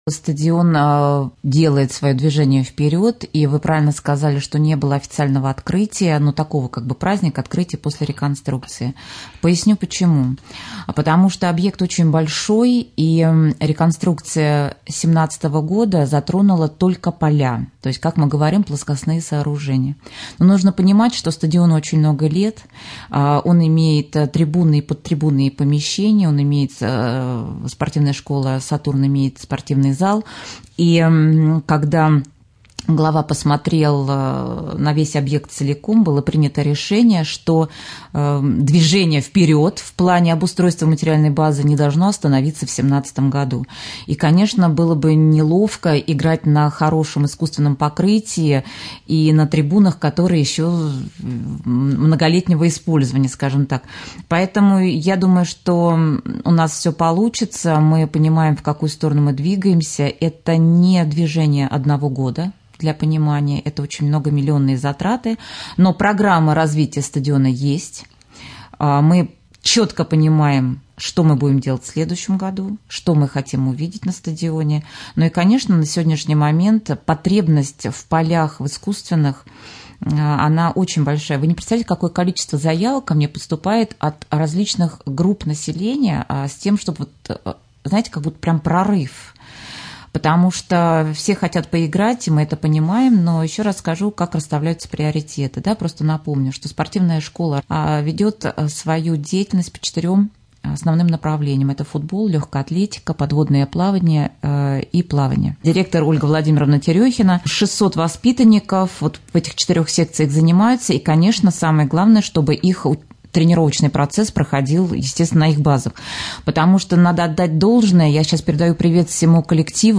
Об этом в ходе прямого эфира на Раменском радио 1 ноября, рассказала председатель Комитета социального развития, спорта и молодежной политики администрации Раменского района Елена Володина.
Подробнее слушайте в интервью с Еленой Володиной ниже.